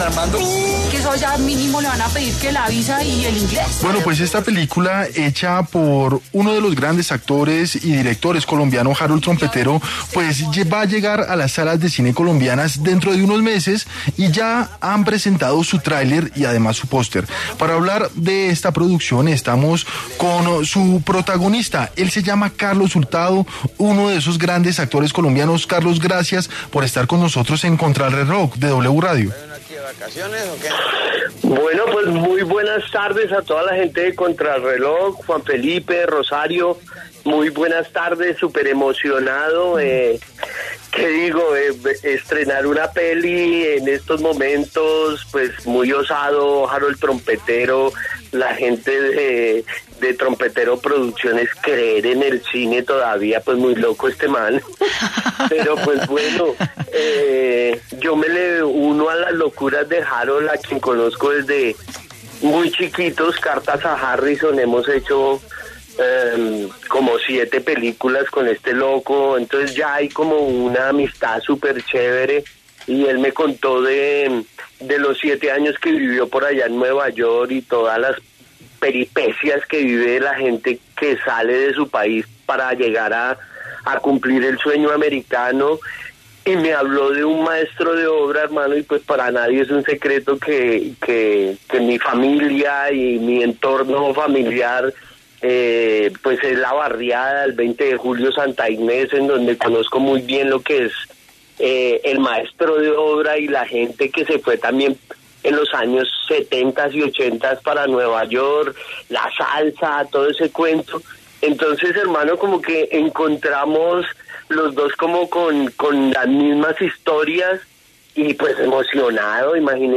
Carlos Hurtado, actor colombiano, habló de su personaje y del proceso de rodaje a Contrarreloj.